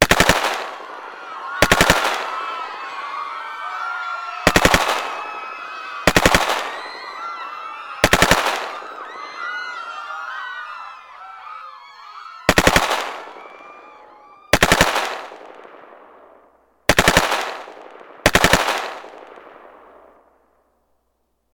На этой странице представлены аудиозаписи, имитирующие звуки выстрелов.
Убийство заложников автоматной очередью звук